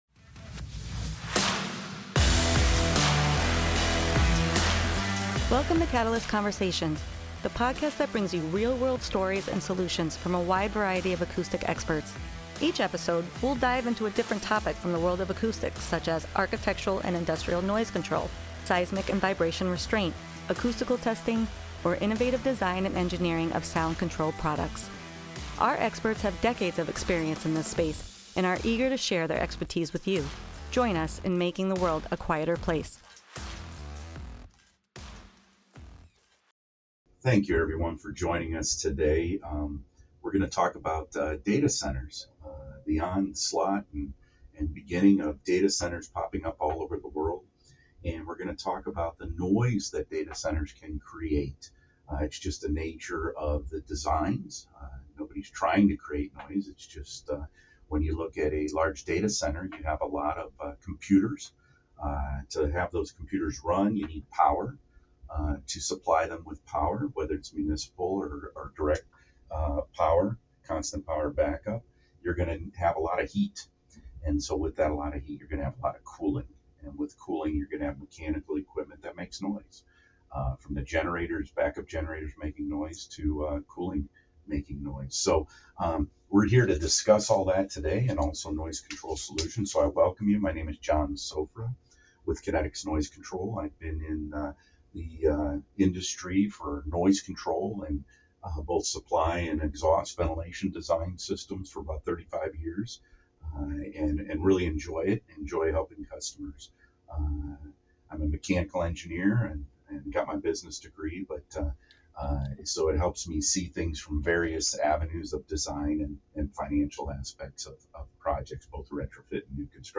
as they sit down for a thorough discussion on the noise issues data centers face and solutions that can be implemented.